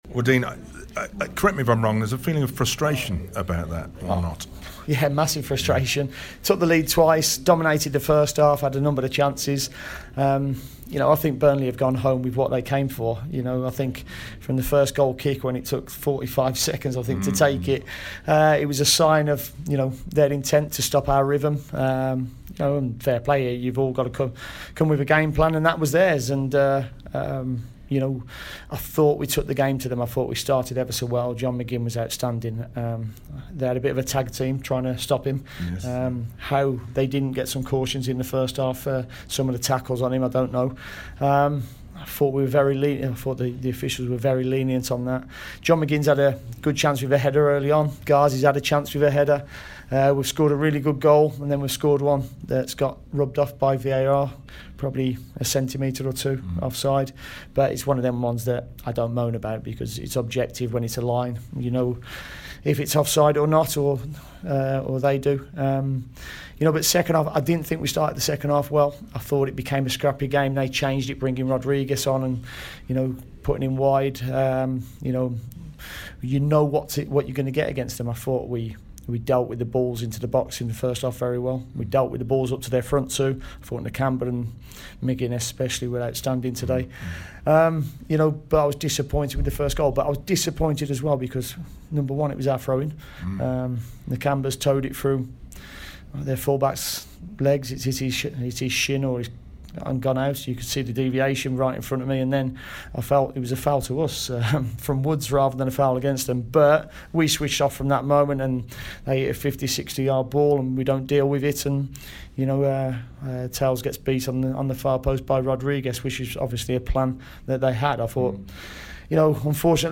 LISTEN: Villa manager Dean Smith reacts to the 2-2 home draw with Burnley